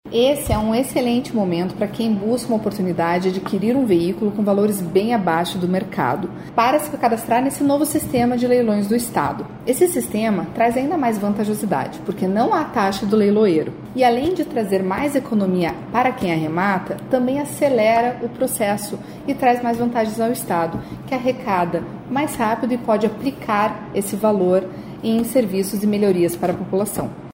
Sonora da secretária da Administração e Previdência em exercício, Luiza Corteletti, sobre o rimeiro leilão de veículos no novo sistema do Estad